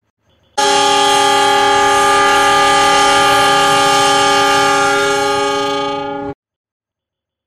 Nada Dering Klakson Kereta Api
Suara klakson kereta api yang khas ini cocok banget buat dijadikan nada dering Hp, notifikasi WA, atau bahkan alarm bangun tidur.
nada-dering-klakson-kereta-api-id-www_tiengdong_com.mp3